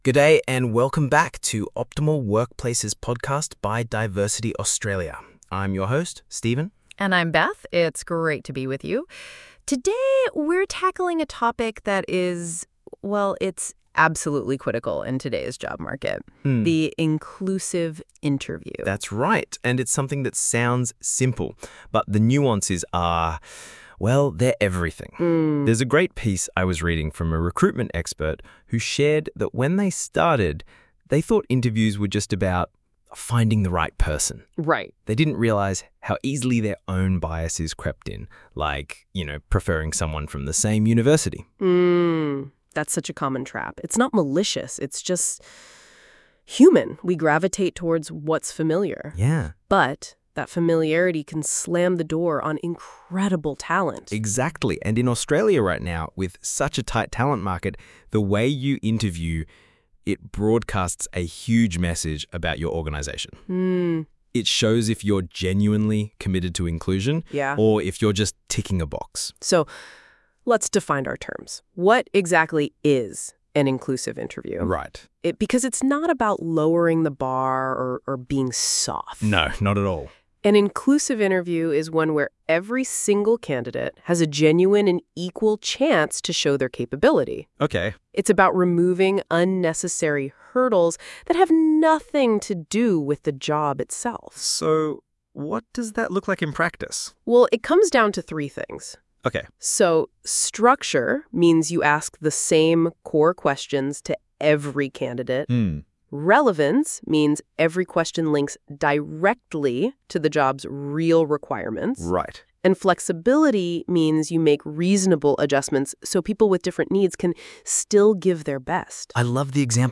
Listen to a conversational overview of this post on the Diversity Australia Optimal Workpalces Podcast  (6 min):